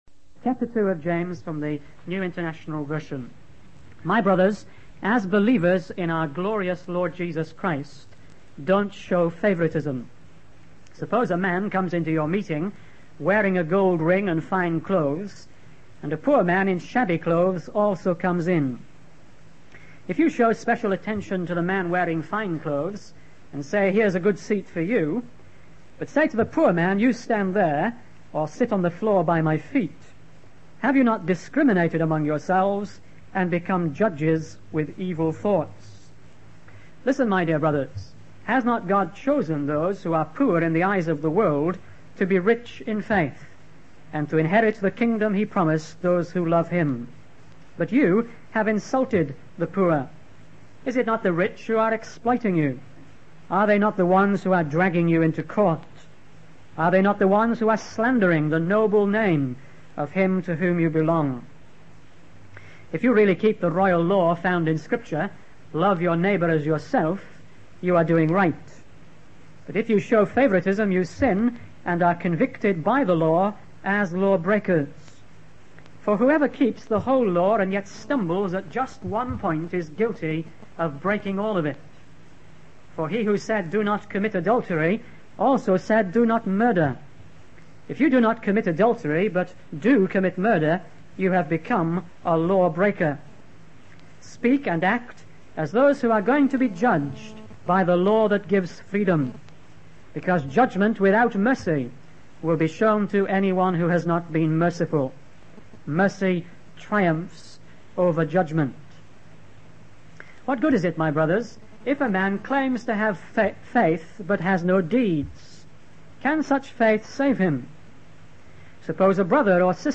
In this sermon, the preacher focuses on the issue of making distinctions and judging others with evil motives. He emphasizes the sin of inconsistency and how it can bring Christians down.